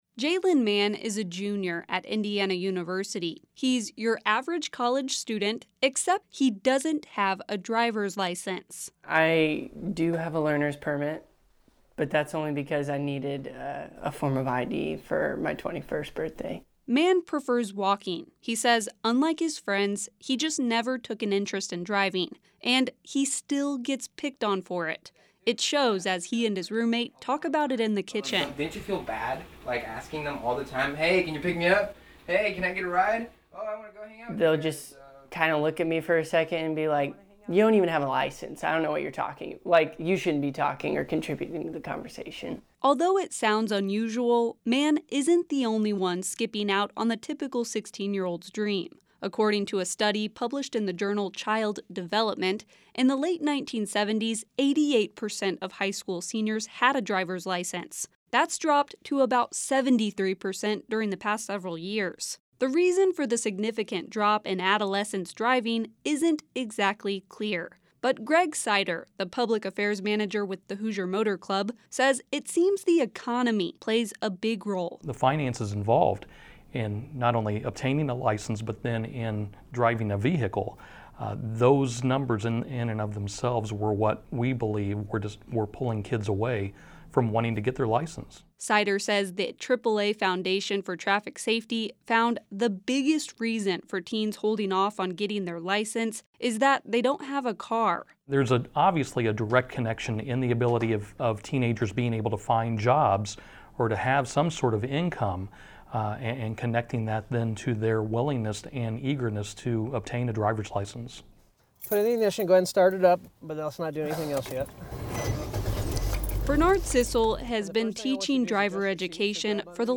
He’s been teaching driver education for the last 20 years, and he says he loves working with the teens.